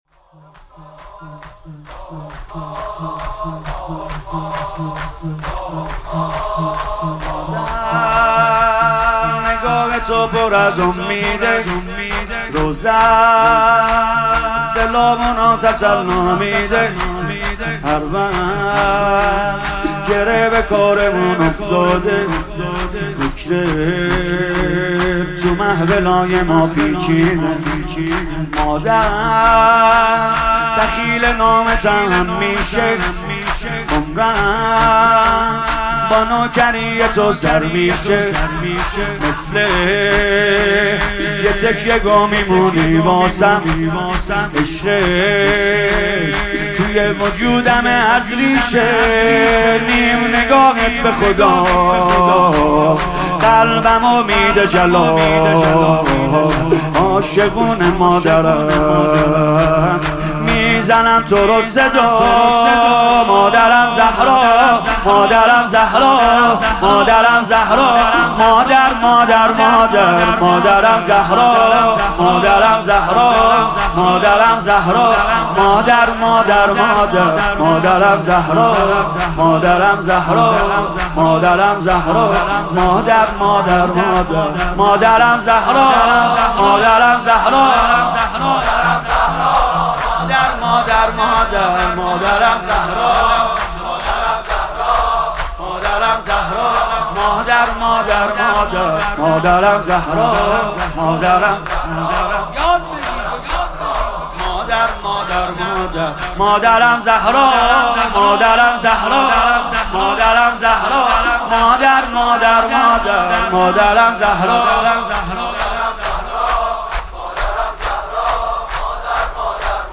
مداحی و نوحه
(شور)